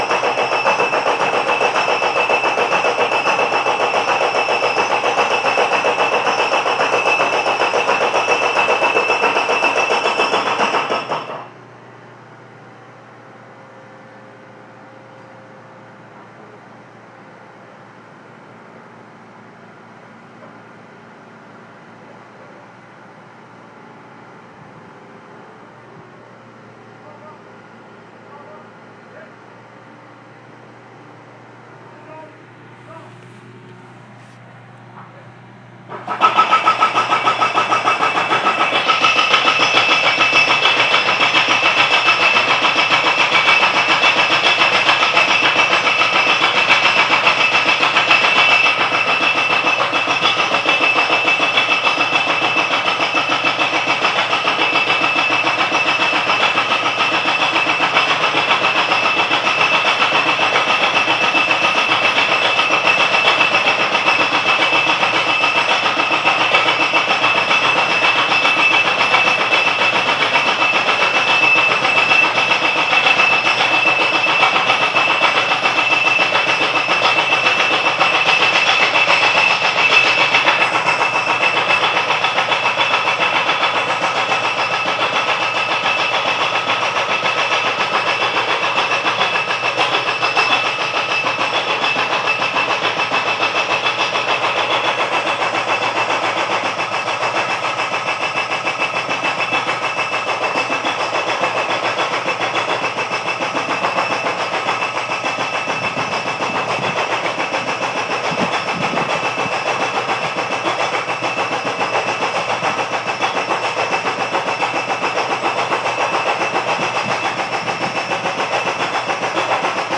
The sound of a huge drill next to our house